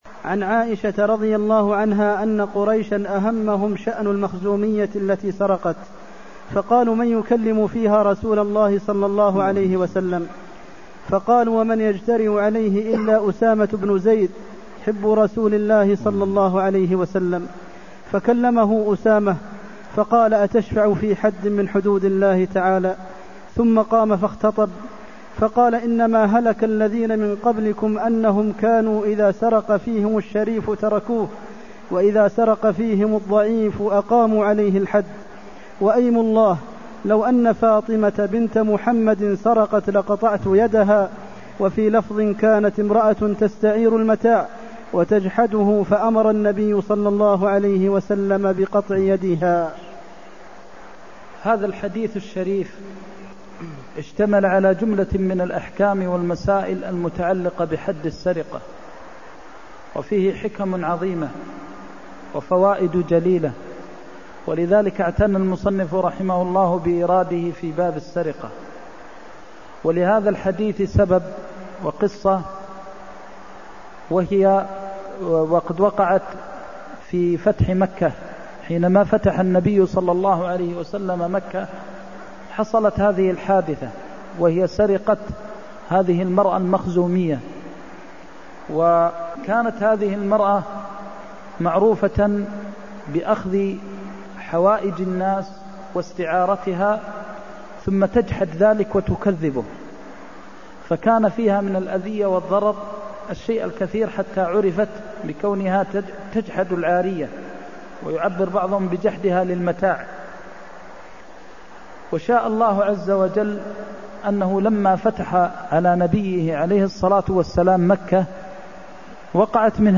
المكان: المسجد النبوي الشيخ: فضيلة الشيخ د. محمد بن محمد المختار فضيلة الشيخ د. محمد بن محمد المختار وايم الله لو أن فاطمة بنت محمد سرقت لقطعت يدها (336)